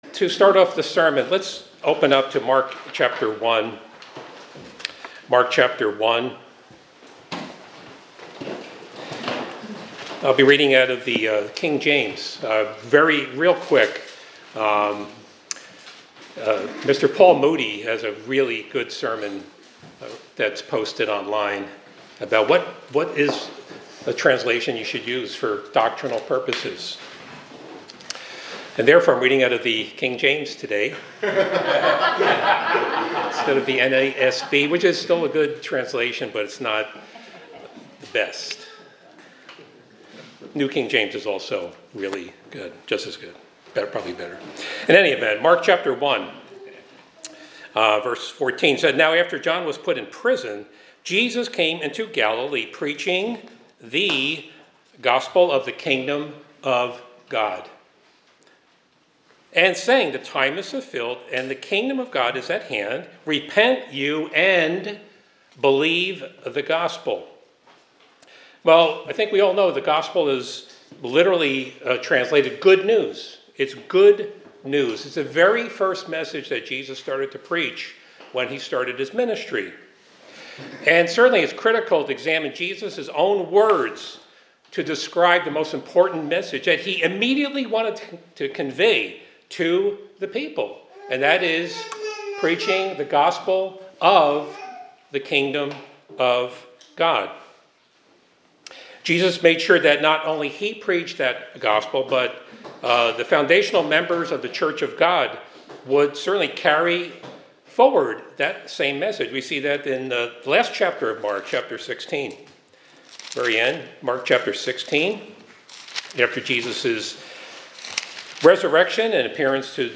Given in Hartford, CT